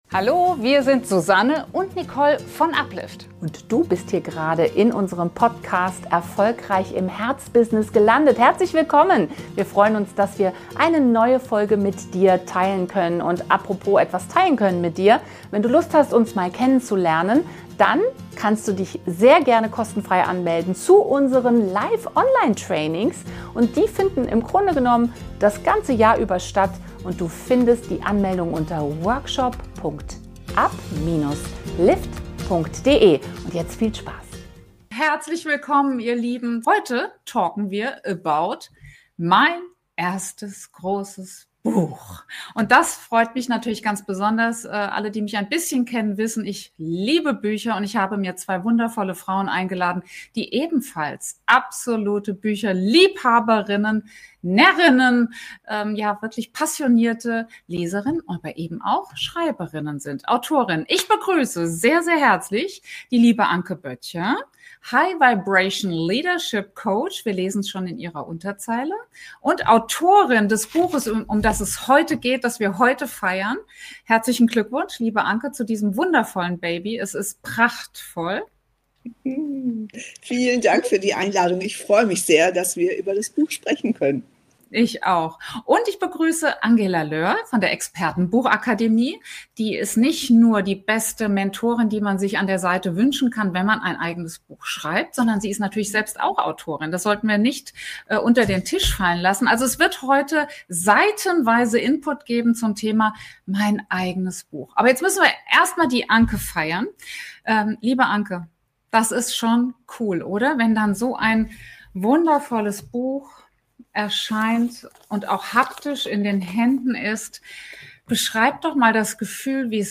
„Das eigene Buch“ Interview